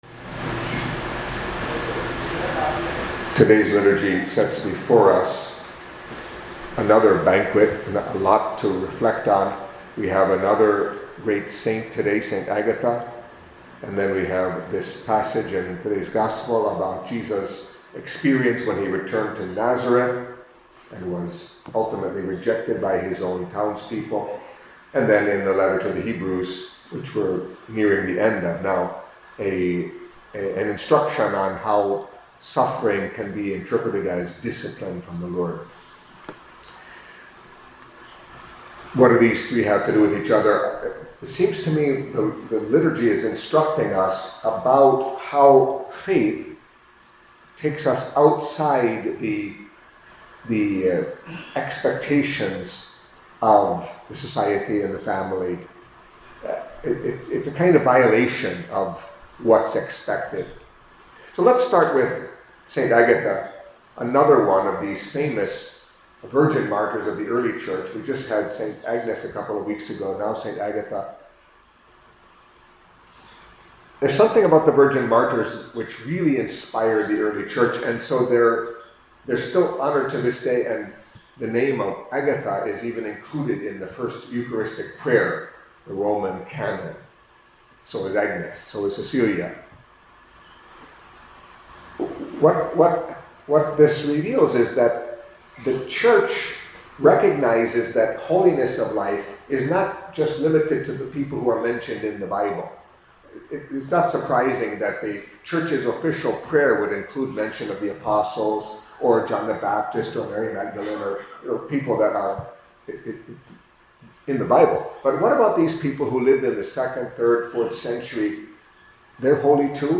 Catholic Mass homily for Wednesday of the Fourth Week in Ordinary Time